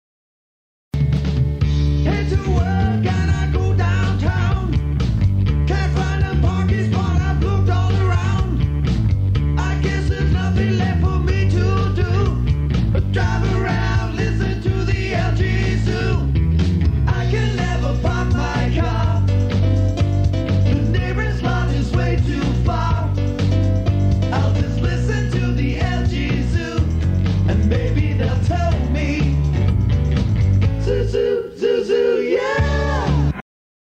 a parody